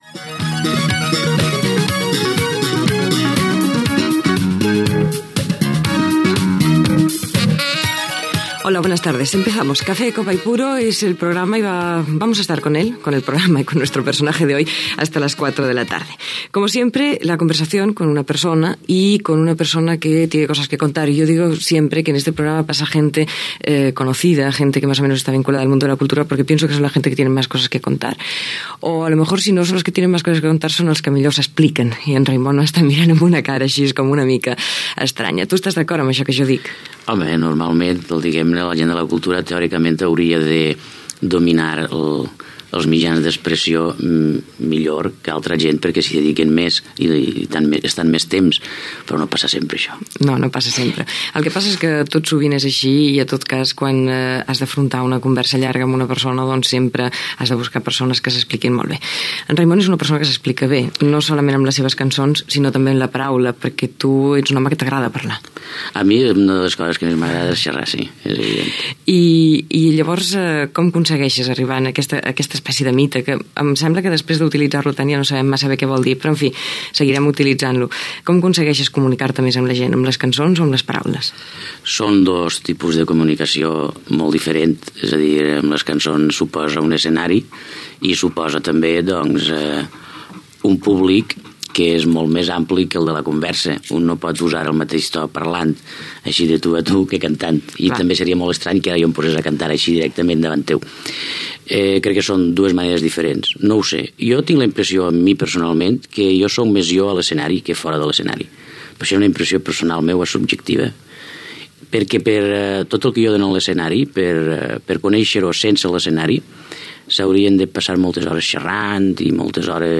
Salutació, presentació i entrevista al cantant Raimon.
Entreteniment